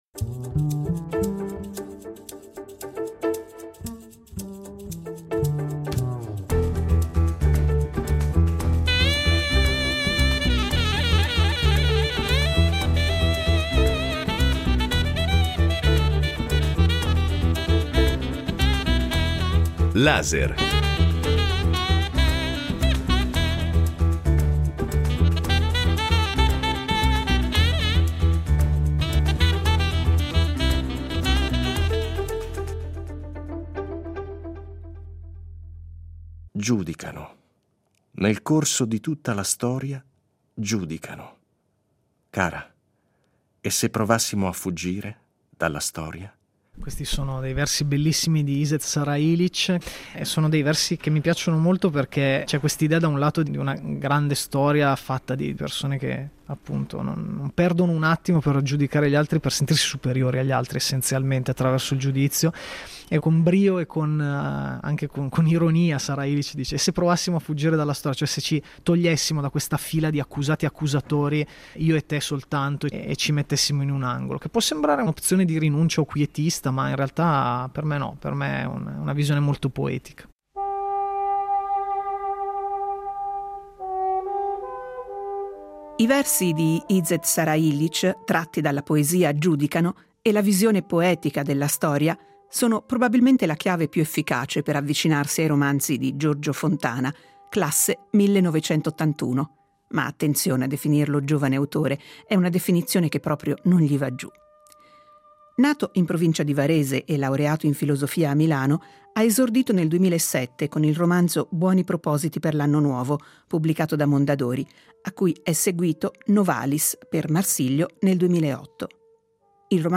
"Prima di noi" (Sellerio, 2020) è un romanzo saga di oltre 900 pagine in cui la storia personale della famiglia Sartori si intreccia con quella dell’Italia, in una sorta di grande affresco dalla Prima guerra mondiale ai giorni nostri. Dei suoi romanzi, del suo rapporto con la scrittura, ma anche del compito che uno scrittore si assume nei confronti delle storie che narra, ci ha parlato Giorgio Fontana in un "Laser" incontri realizzato al Festivaletteratura di Mantova.